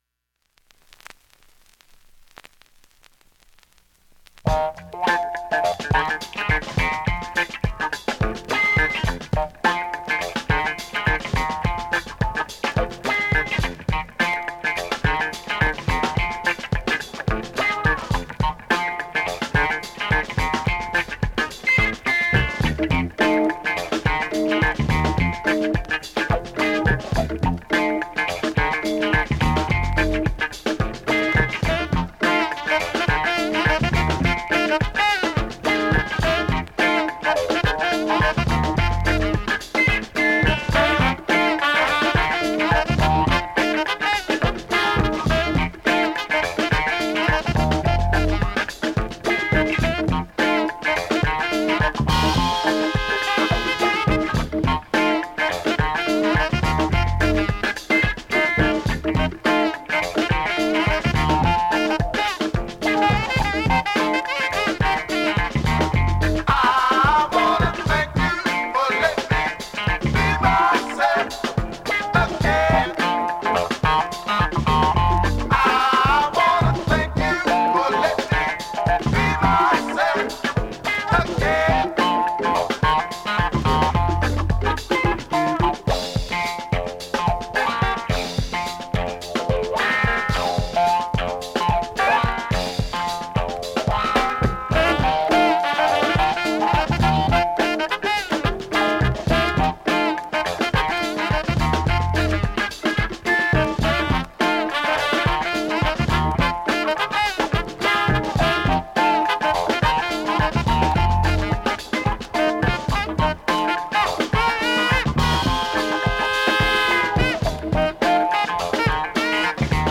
現物の試聴（両面すべて録音時間６分２１秒）できます。